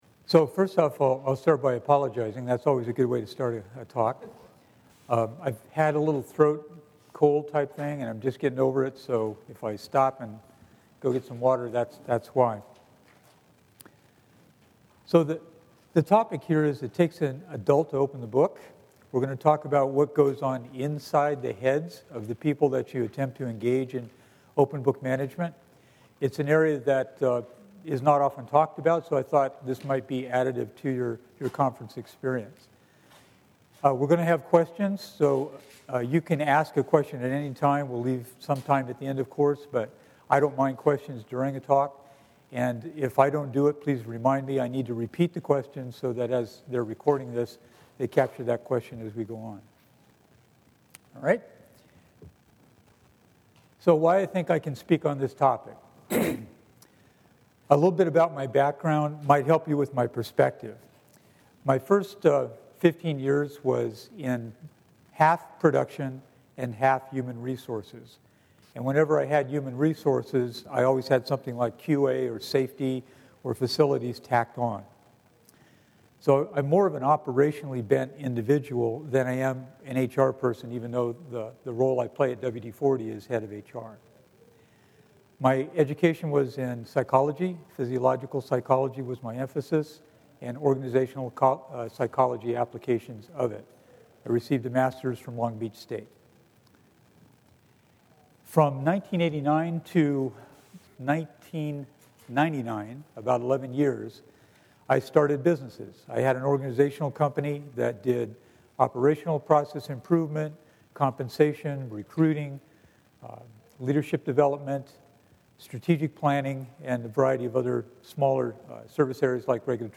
It Takes an Adult to Open the Books , from the 22 nd Annual Gathering of Games